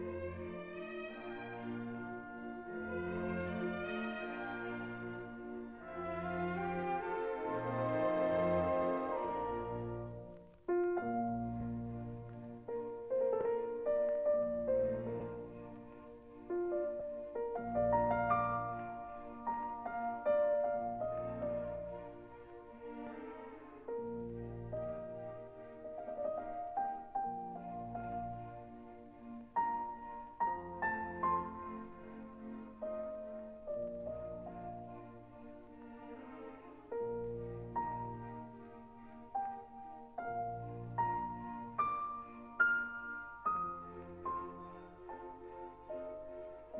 stereo recording